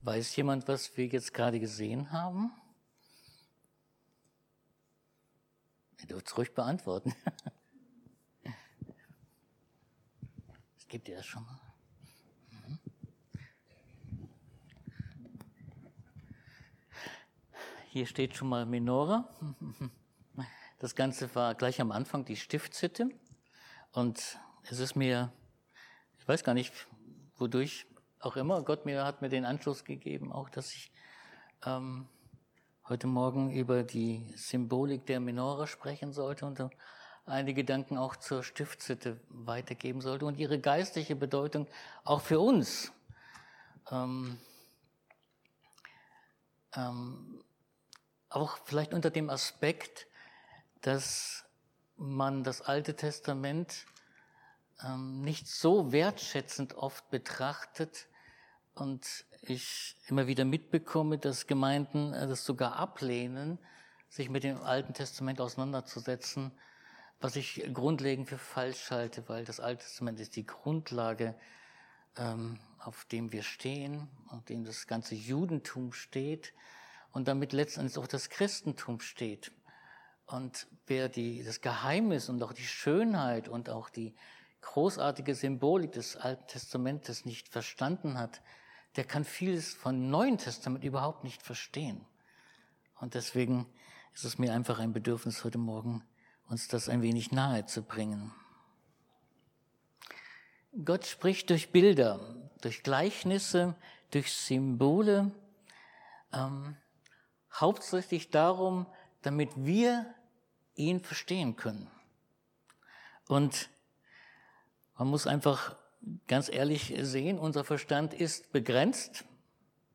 Sacharja 4:6 Dienstart: Predigt Die Menora erscheint als starkes biblisches Bild für Gottes Licht, Gegenwart und Treue.